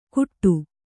♪ kuṭṭu